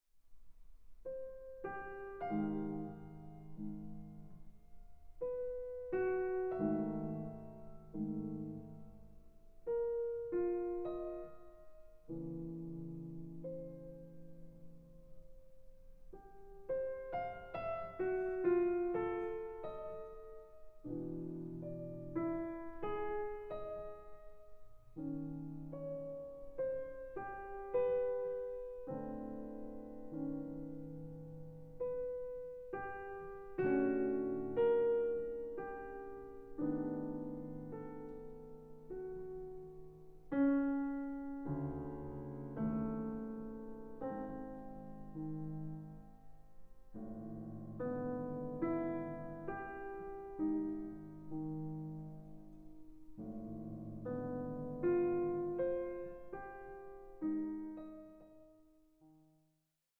a work of stark introspection and formal ingenuity.